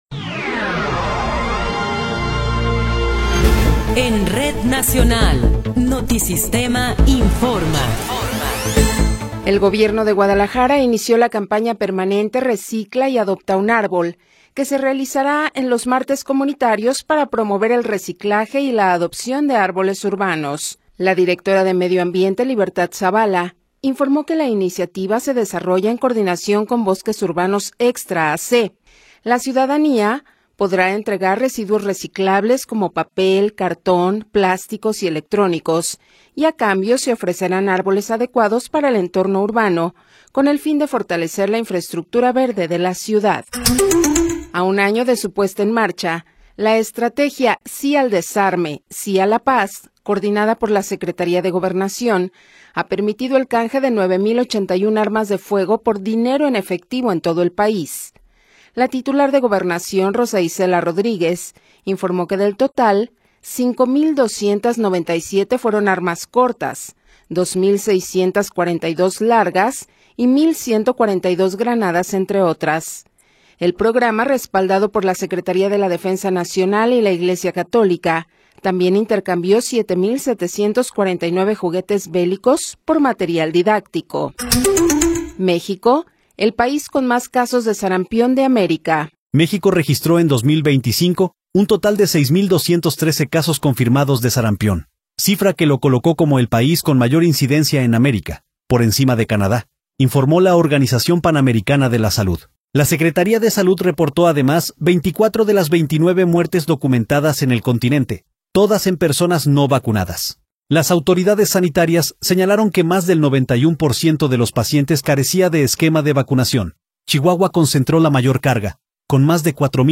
Noticiero 16 hrs. – 17 de Enero de 2026
Resumen informativo Notisistema, la mejor y más completa información cada hora en la hora.